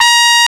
Index of /m8-backup/M8/Samples/FAIRLIGHT CMI IIX/BRASS1
BRASS1.WAV